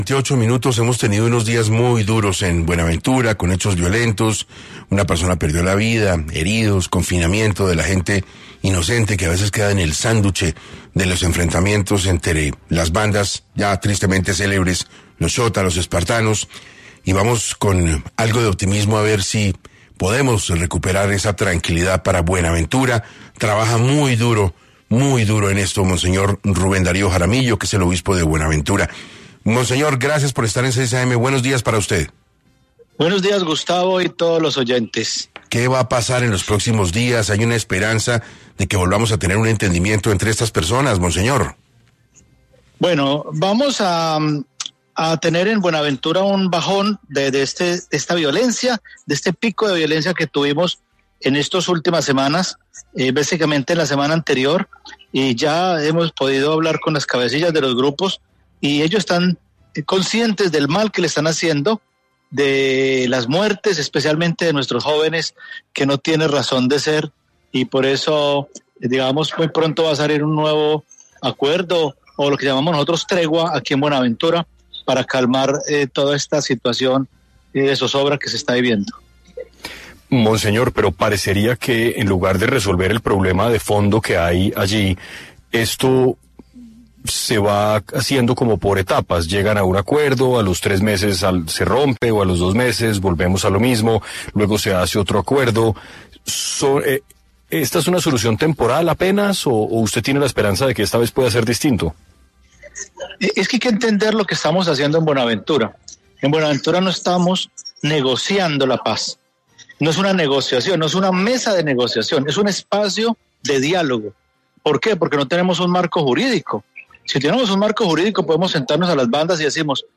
En diálogo con 6AM de Caracol Radio, el obispo de Buenaventura, monseñor Rubén Darío Jaramillo, explicó que, pese a la difícil situación, se abre un camino de esperanza gracias a los diálogos que se mantienen con las cabecillas de los grupos armados.